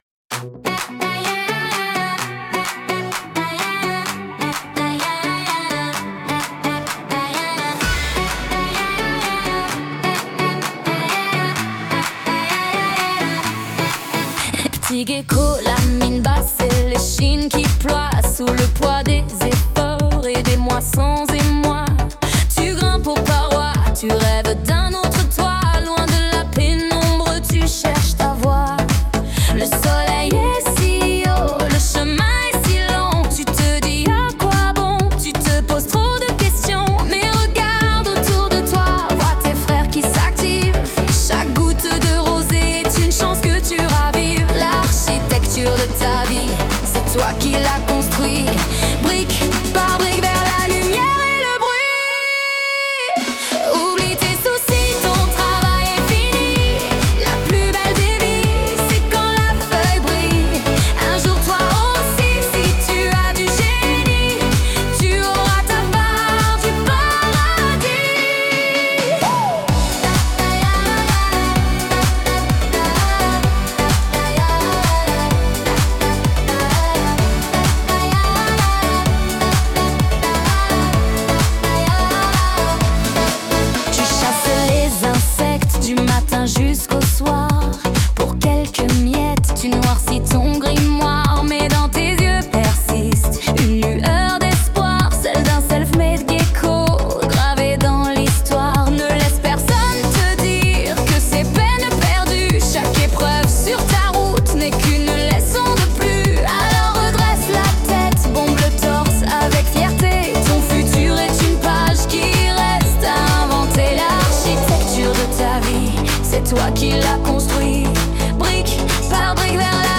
Les paroles étaient simples et entraînantes :